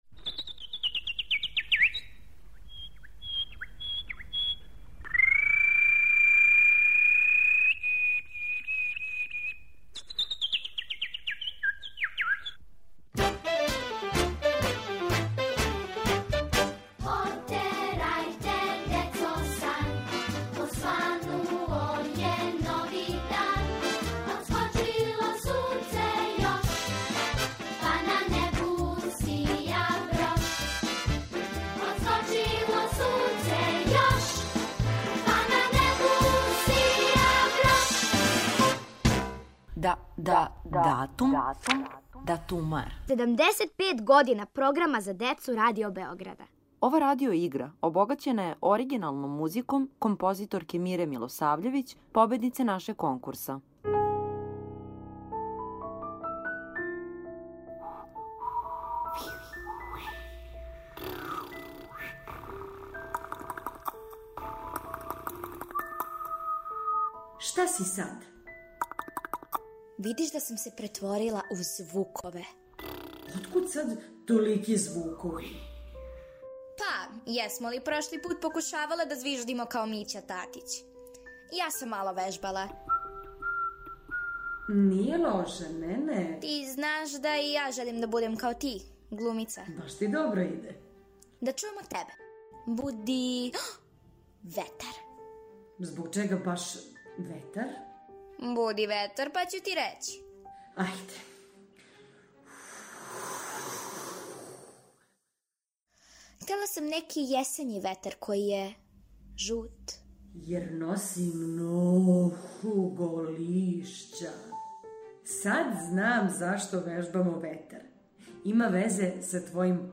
Поводом 75 година Програма за децу и младе Радио Београда, будимо вас четвртим делом радио игре, обогаћене музиком композиторке Мире Милосављевић, победнице нашег конкурса.